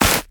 Sfx_creature_penguin_hop_land_10.ogg